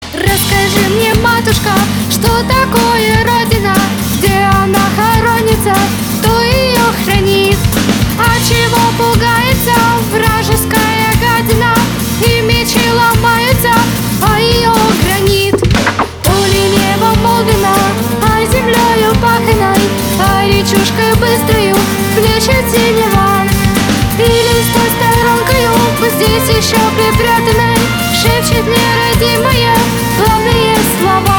• Качество: 320, Stereo
патриотические
русский рок
фолк-рок